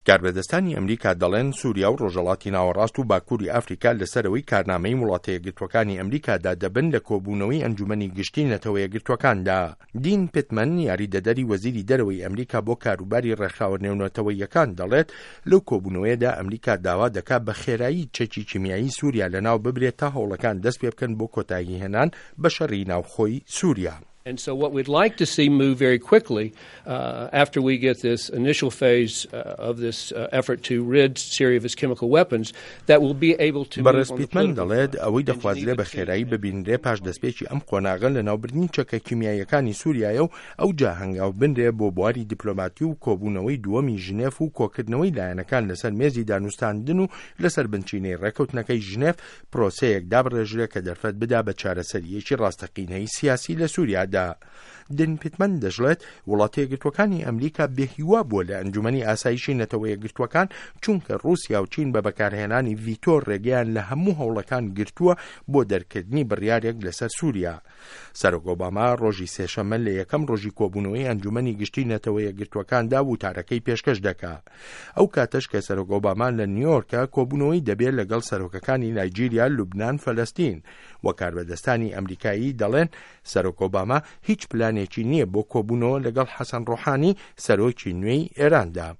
ڕاپۆرتی کارنامه‌ی ئه‌مه‌ریکا